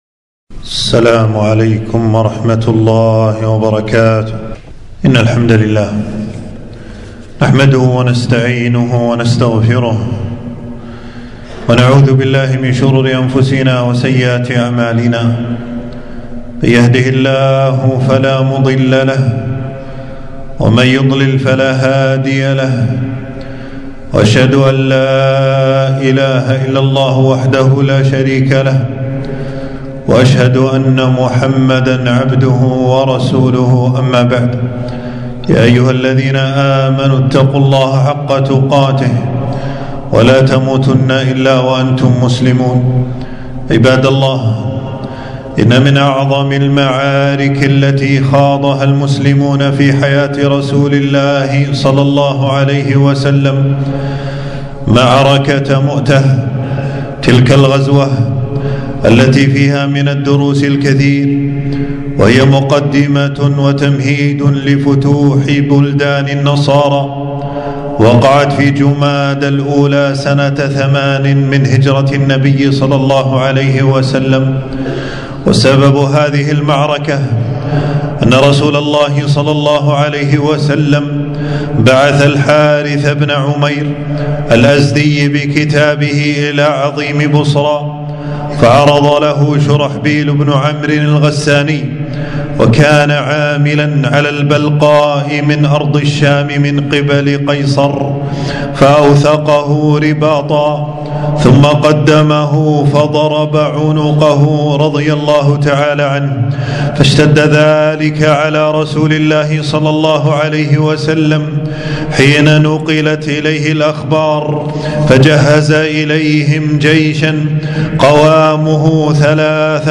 تنزيل تنزيل التفريغ خطبة بعنوان: معركة مؤتة دروس وعبر .
في مسجد السعيدي بالجهراء